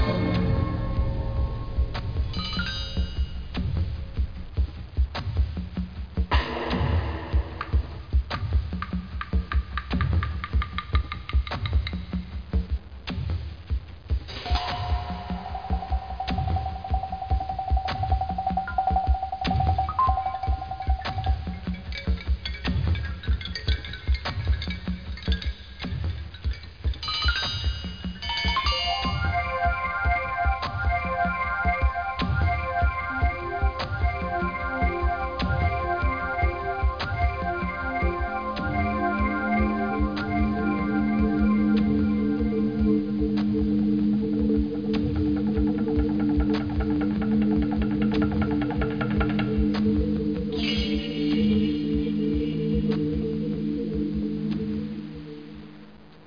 1 channel
AMBIENT3.mp3